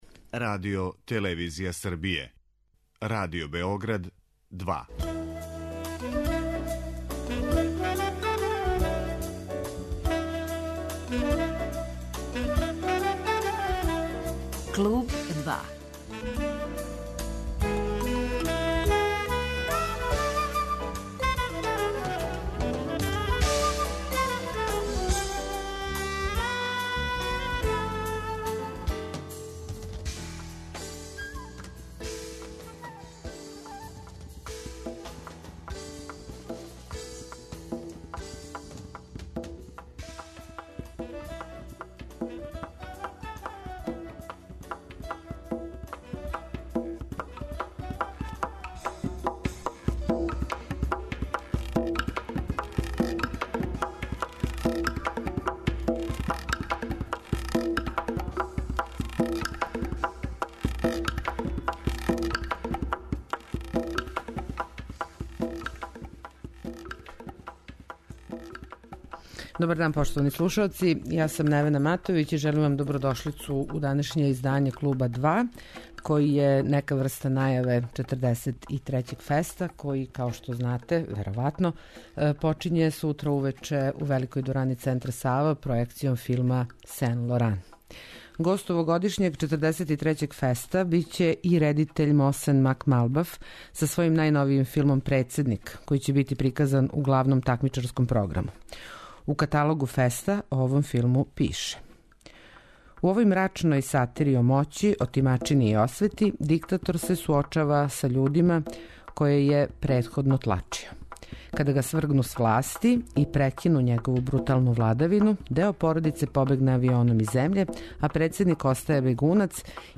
Разговор са Мосеном Макмалбафом
Разговор који ћете чути снимљен је 2013. године на 16. Филмском фестивалу у Мотовуну, где је Макмалбаф добио традиционалну награду Маверик .